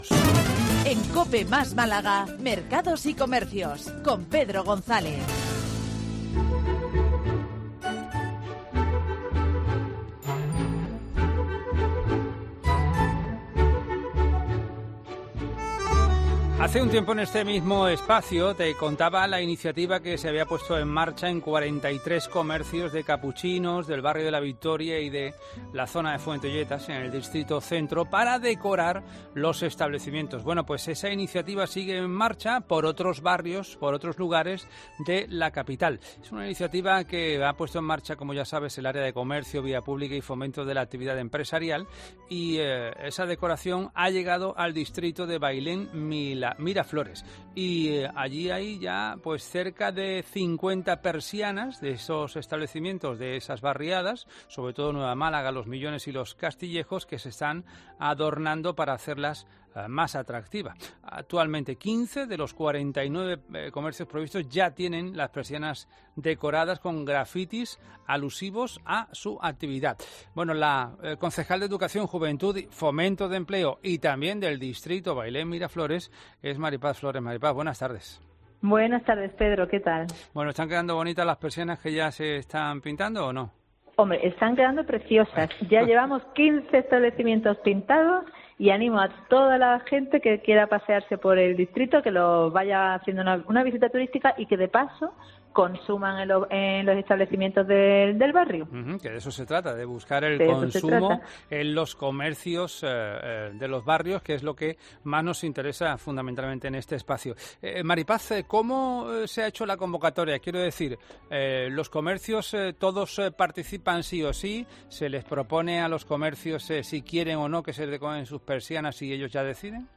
La edil de Bailén Miraflores, María Paz Flores ha contado de COPE Más Málaga en qué consiste esta iniciativa.